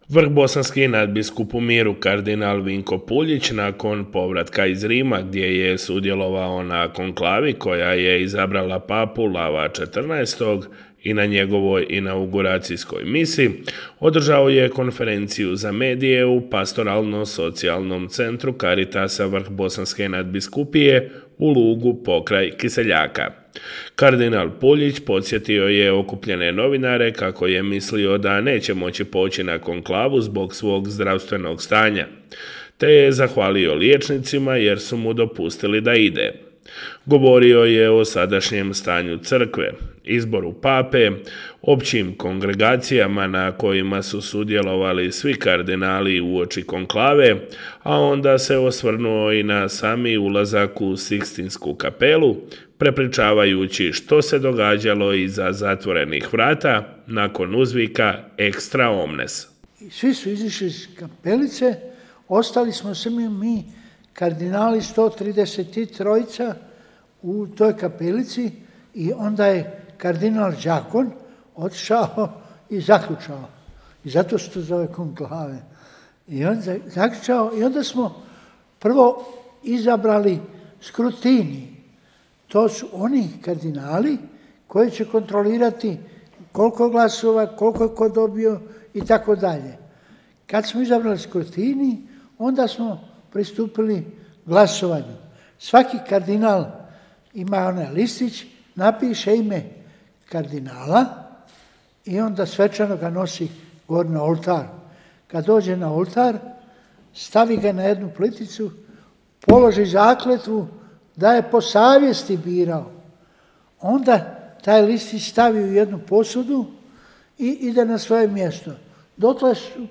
Vrhbosanski nadbiskup u miru kardinal Vinko Puljić nakon povratka iz Rima gdje je sudjelovao na konklavi koja je izabrala papu Lava XIV. održao je konferenciju za medije u srijedu 21. svibnja u Pastoralno-socijalnom centru Caritasa Vrhbosanske nadbiskupije u Lugu pokraj Kiseljaka.